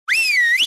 s_whistle.mp3